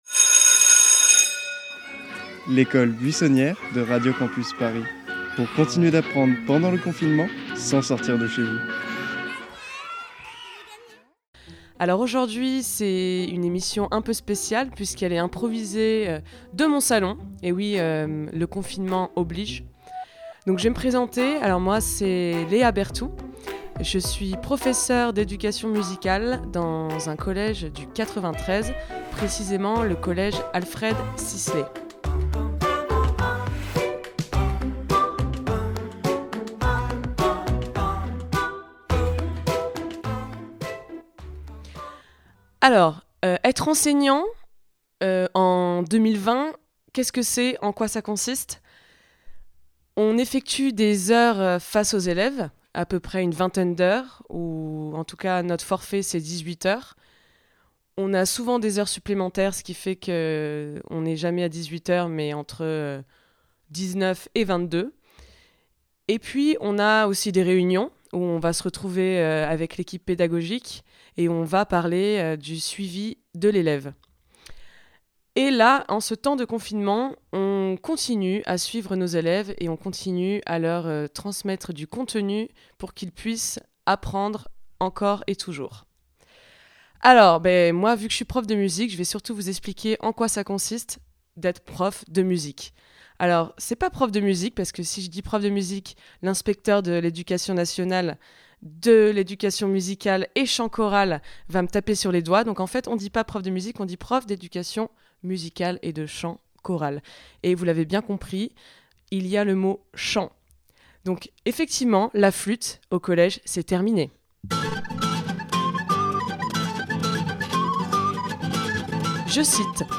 Apprenez tout sur son métier et découvrez des petites surprises ... musicales concoctées par ses élèves !